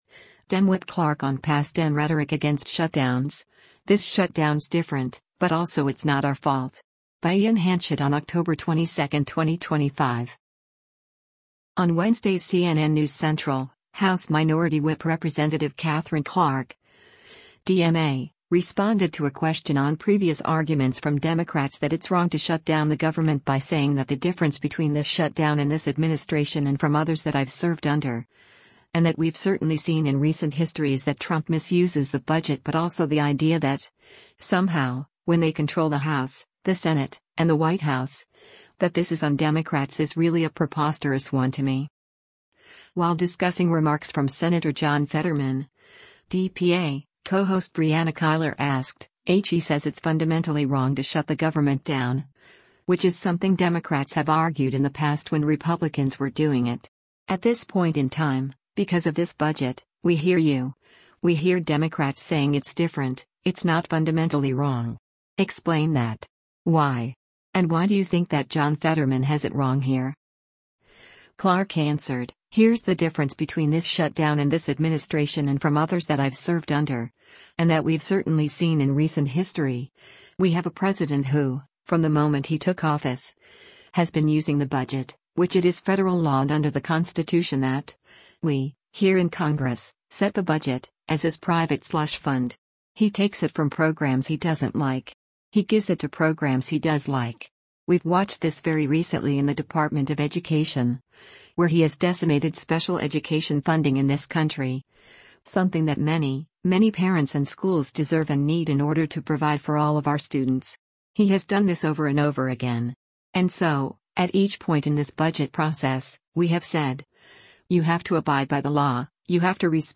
While discussing remarks from Sen. John Fetterman (D-PA), co-host Brianna Keilar asked, “[H]e says it’s fundamentally wrong to shut the government down, which is something Democrats have argued in the past when Republicans were doing it.